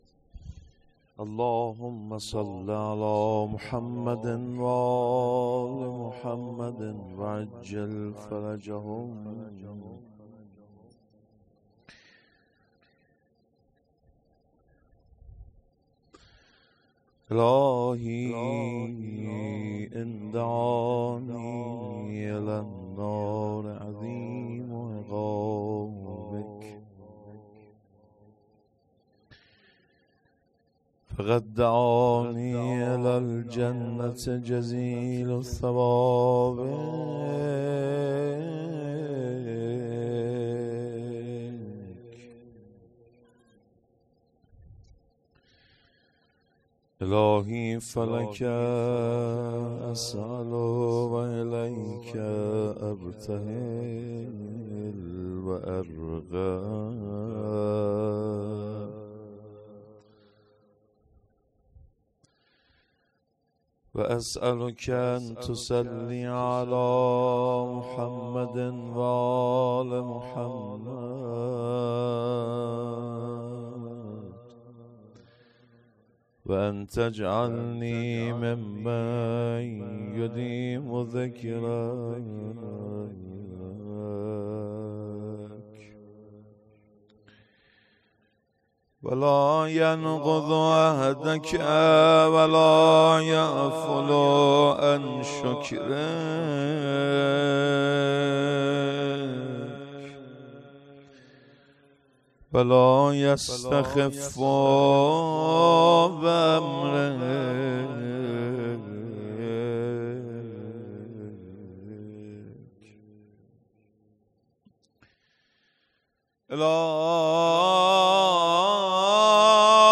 مدح خوانی
شب سوم جشن ولادت حضرت قائم عجل الله ۱۴۰۳